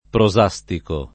[ pro @#S tiko ]